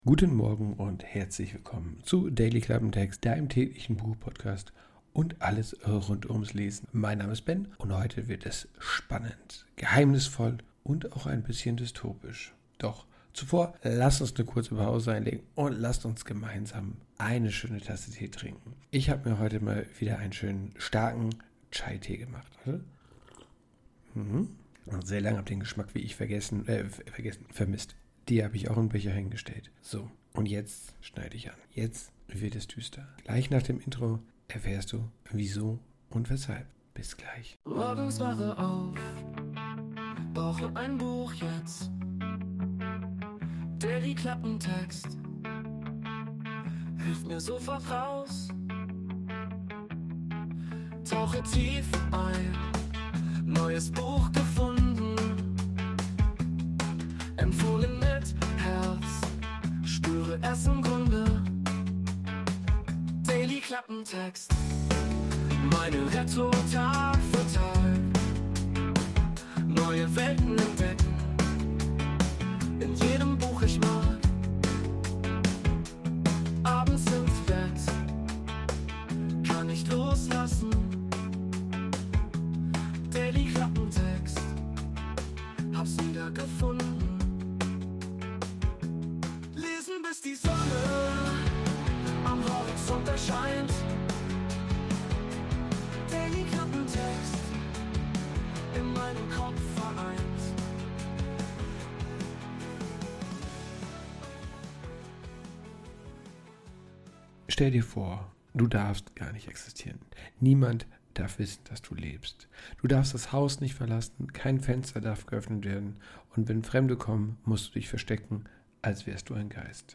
Intromusik: Wurde mit der KI Suno erstellt.